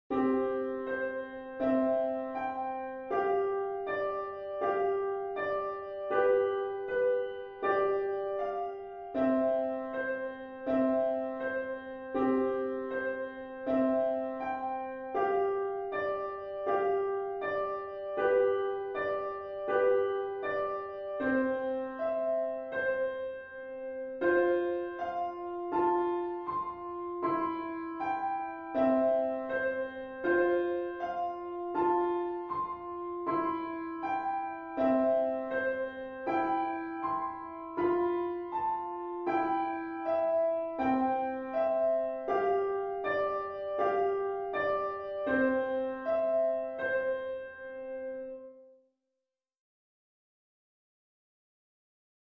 リピートは基本的に省略しています。